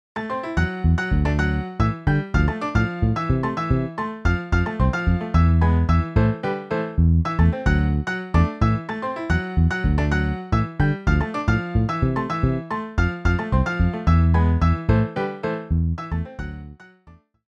2X Trompetas, 2X Trombones, Piano, Bajo